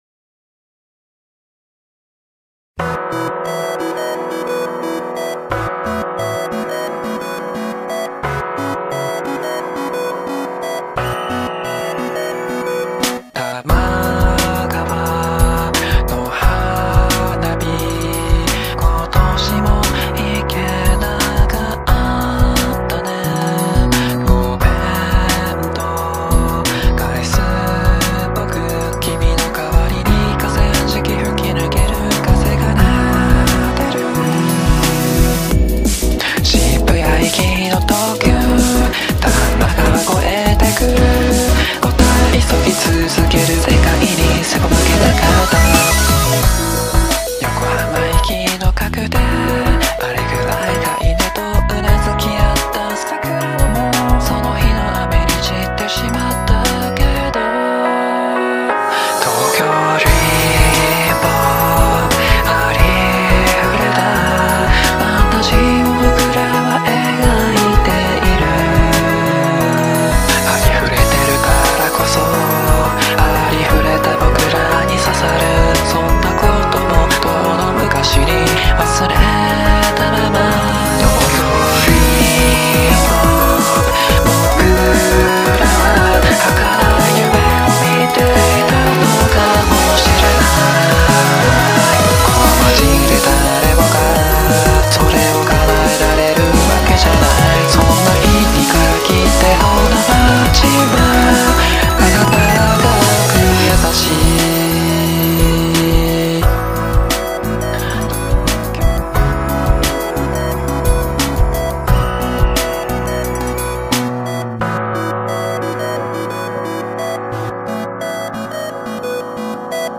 BPM44-176
Audio QualityPerfect (Low Quality)